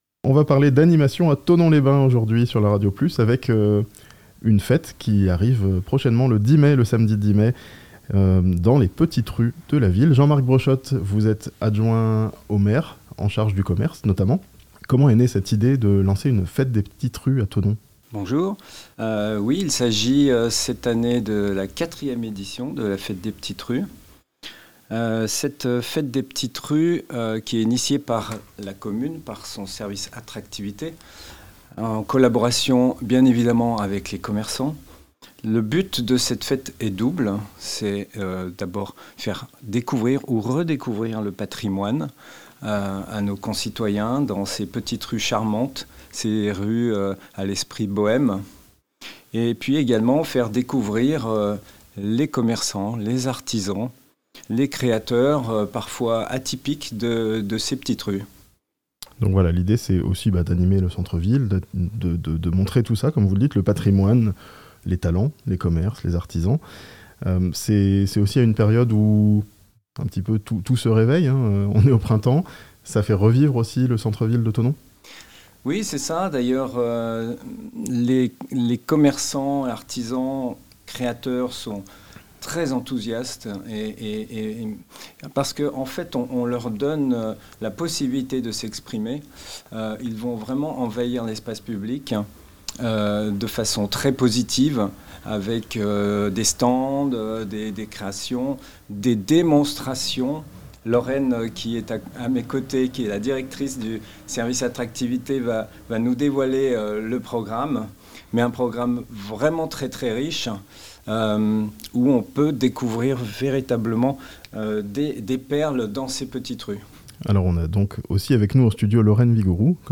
A Thonon, les petites rues seront en fête le 10 mai (interview)